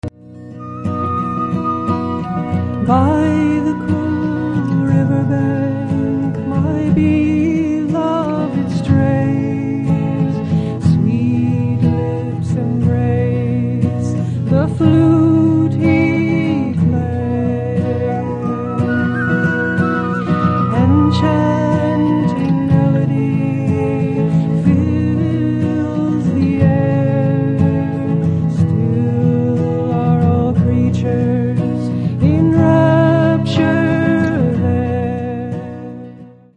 Devotional Songs for Children in English